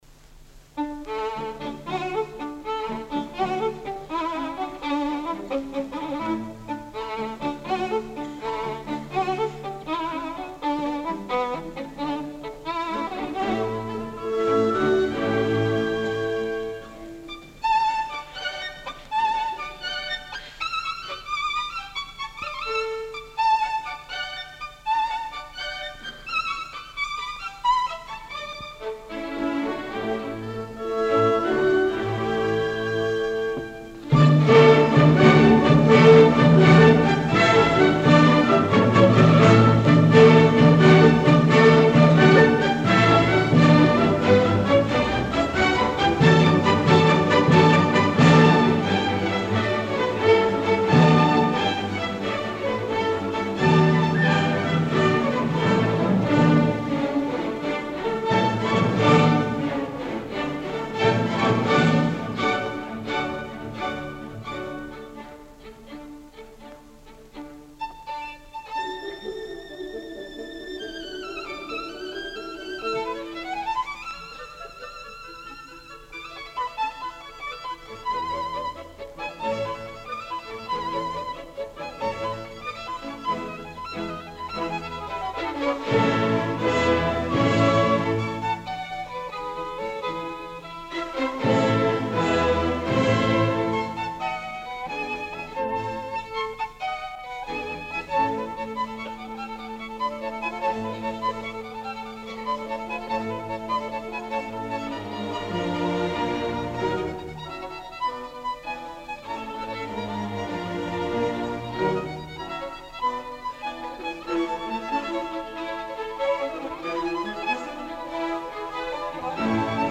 Beethoven – Violin Concerto Concertgebouw Orchestra Amsterdam
Live recording